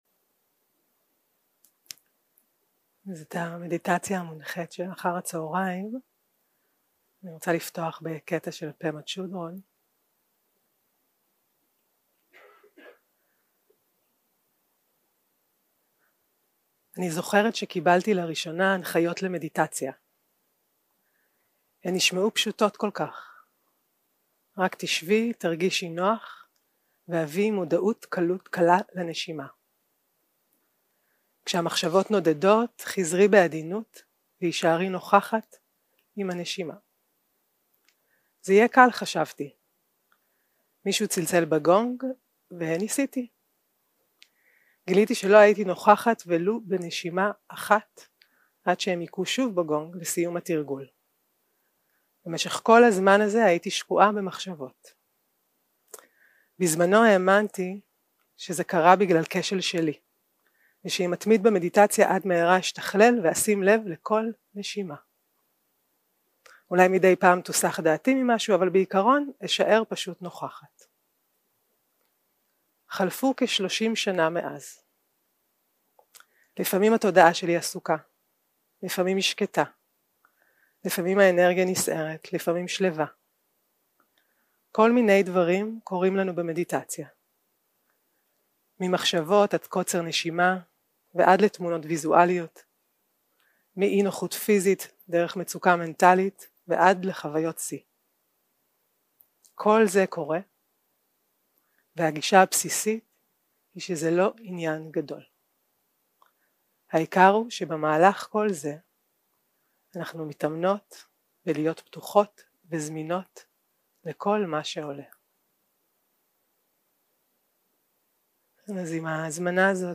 יום 2 - הקלטה 3 - צהרים - מדיטציה מונחית - תשומת לב לגוף, שמיטה וקבלה
יום 2 - הקלטה 3 - צהרים - מדיטציה מונחית - תשומת לב לגוף, שמיטה וקבלה 0:00 0:00 סוג ההקלטה: Dharma type: Guided meditation שפת ההקלטה: Dharma talk language: Hebrew